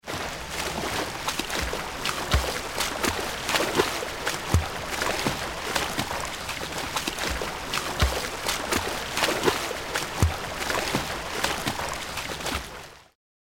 Download Free Swimming Sound Effects | Gfx Sounds
Swimming-in-pool-freestyle-stroke-with-breathing-3.mp3